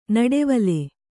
♪ naḍe vale